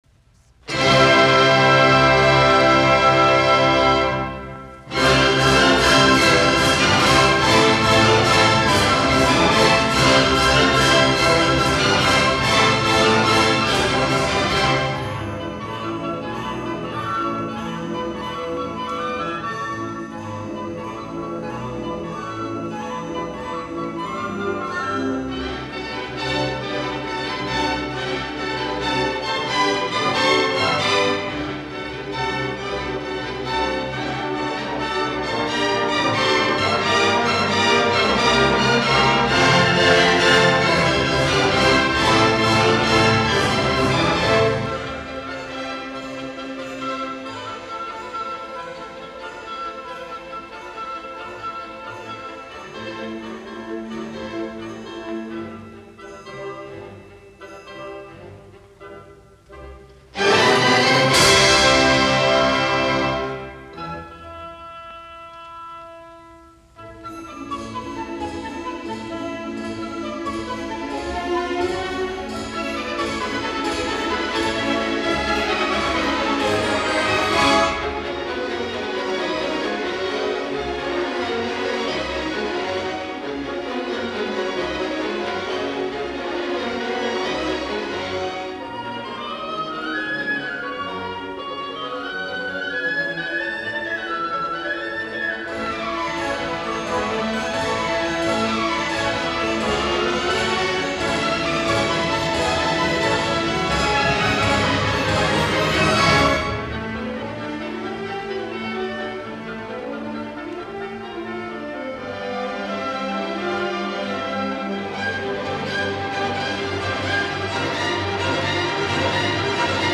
Soitinnus: Ork